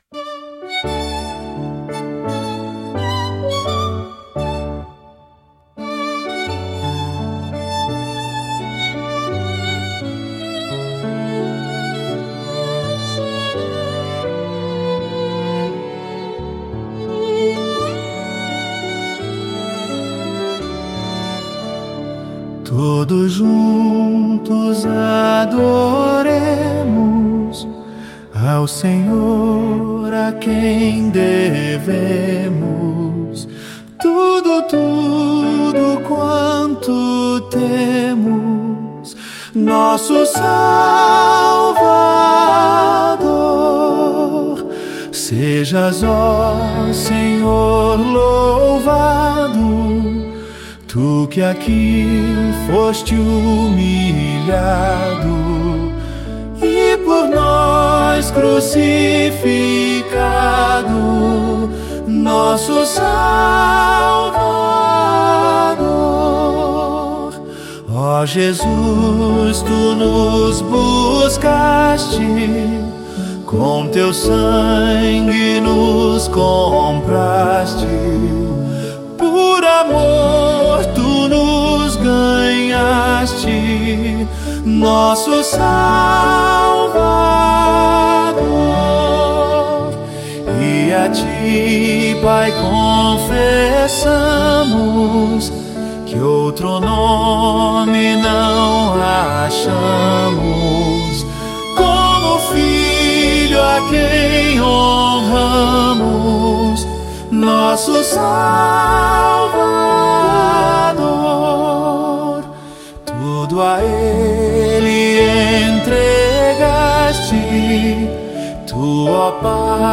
Juntos Adoremos 02:47 8.8.8.5. - Melodia Alemã (Praise The Saviour) texto partitura cifra 1.